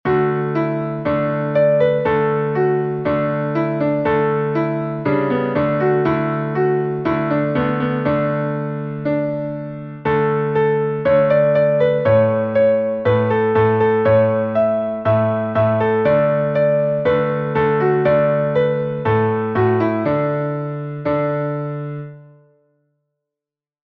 Meter: Irregular
Key: D Major